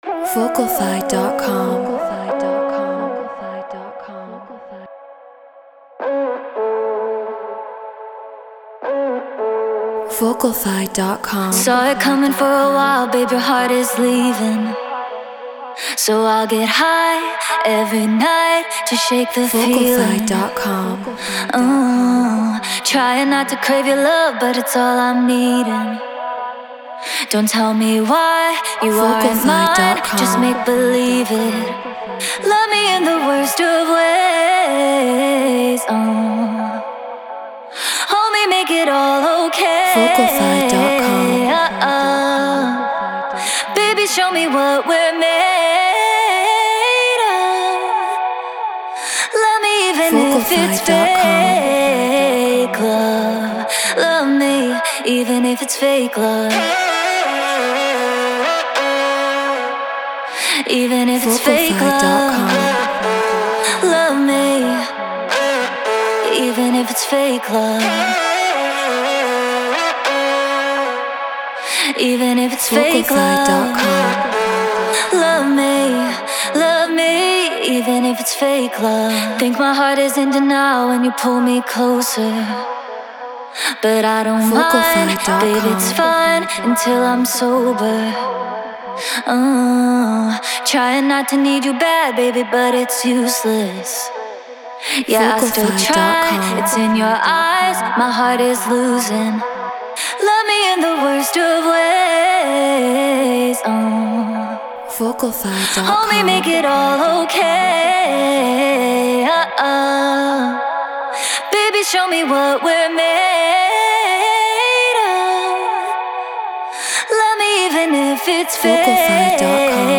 Drum & Bass 170 BPM Amaj